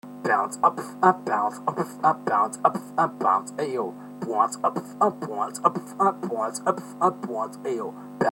house beat
a-обрывистый звук буквы а
ao-сам придумал. короч говорите "ау" на одной ноте, с ломанием голоса.
b-бочка. любой битбоксер должен знать что это.
t-знает каждый.(только в этом бите надо делать звук между "т" и "ц")
вот. качество правда фигня.
Форум российского битбокс портала » Реорганизация форума - РЕСТАВРАЦИЯ » Выкладываем видео / аудио с битбоксом » house beat (темп от 125 до 145 ударов в минуту)